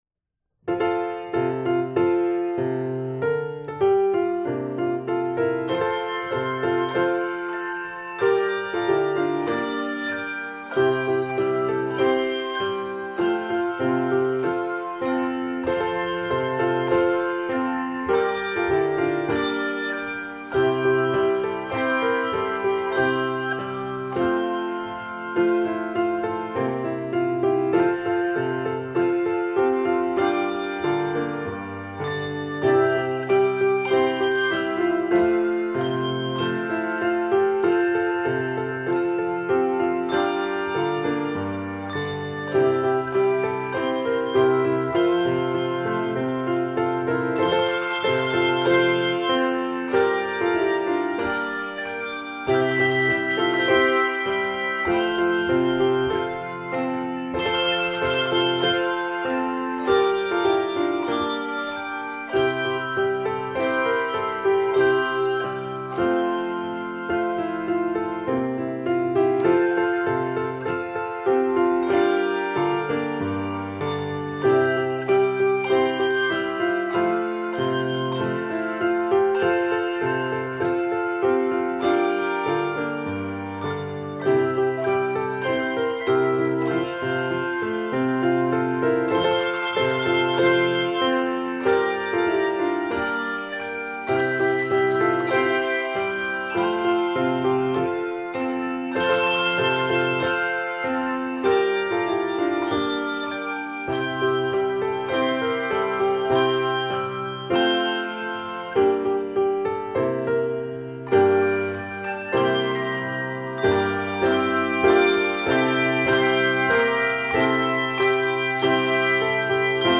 tune in an exquisite blend of ancient and modern.